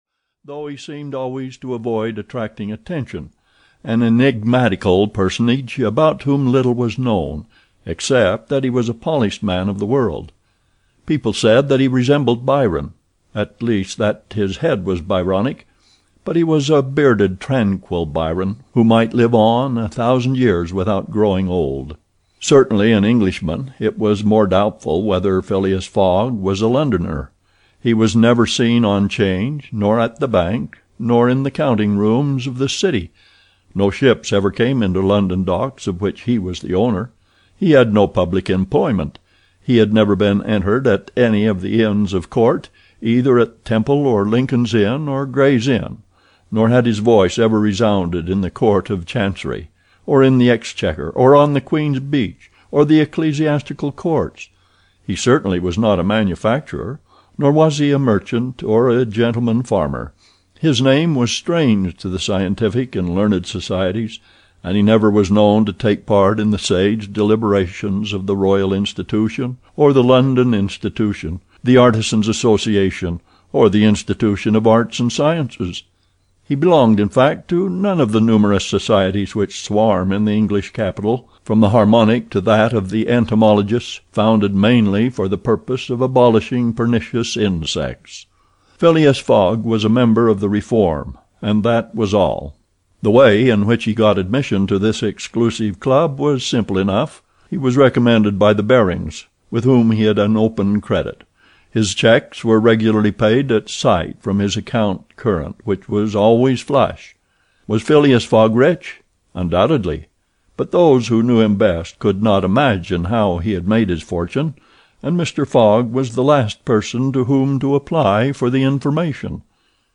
Around the World in 80 Days (EN) audiokniha
Ukázka z knihy